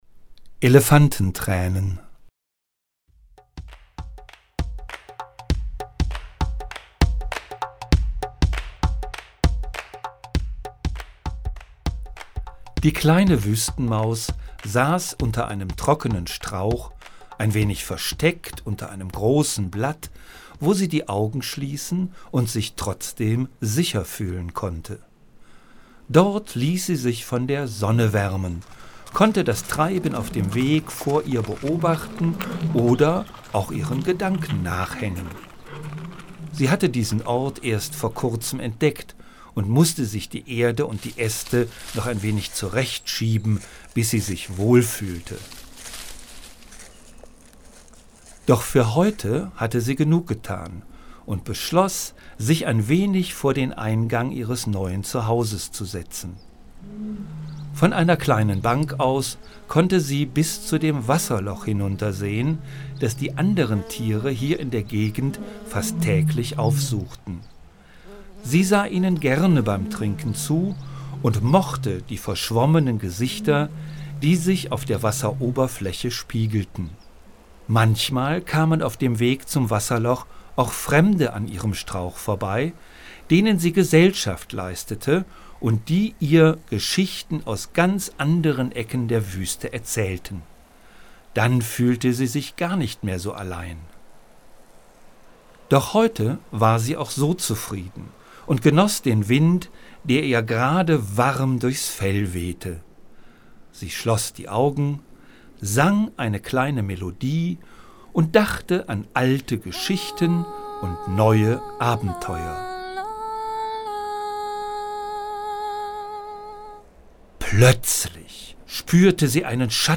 13. Hörspiel
hoerspiel_elefantentraenen_teil1.mp3